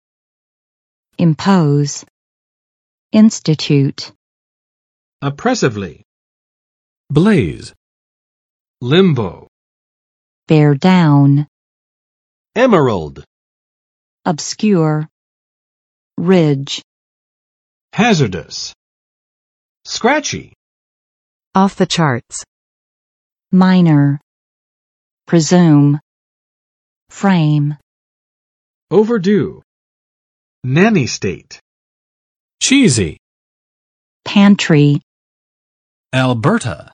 Vocabulary Test - September 1, 2021
[ɪmˋpoz] v. 强制实行
[ˋɪnstətjut] v. 制定
[blez] n. 火灾；熊熊燃烧
impose.mp3